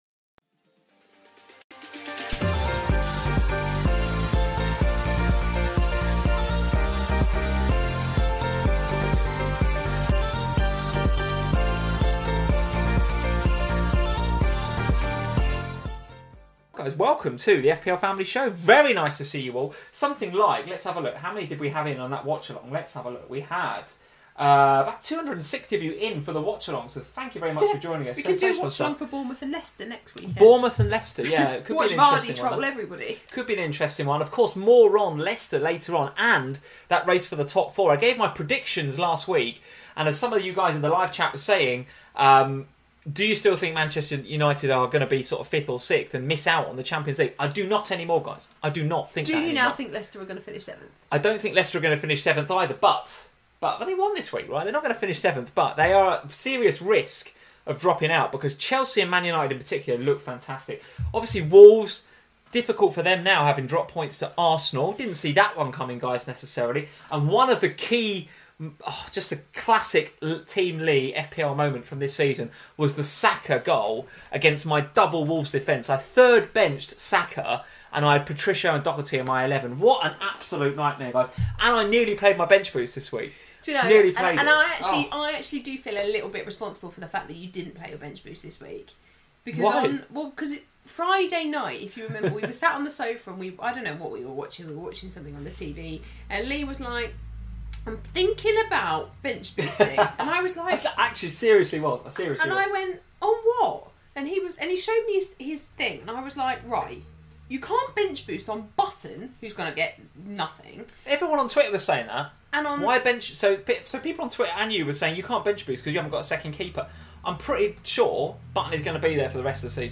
Welcome to FPL Family, a chat show dedicated to all things Fantasy Premier League.